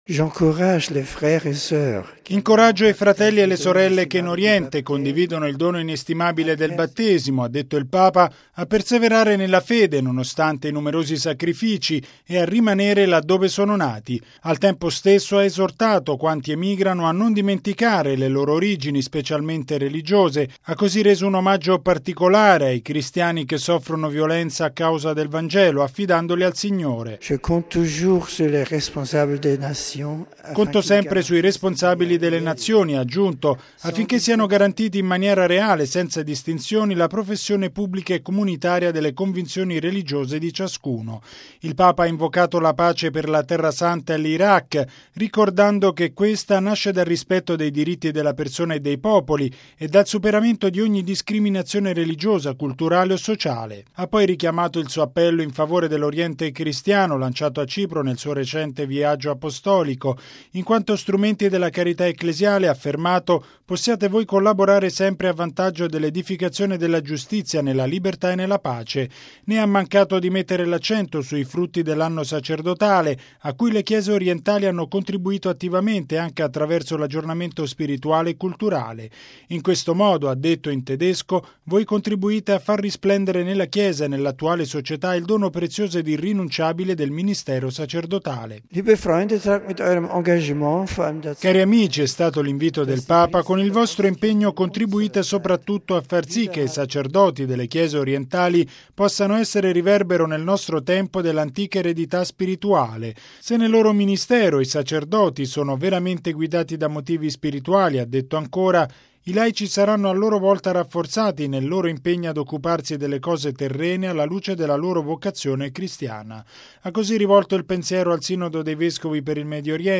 Nel suo discorso, pronunciato in più lingue, il Papa ha messo l’accento sulle difficili condizioni in cui vivono tanti cristiani del Medio Oriente.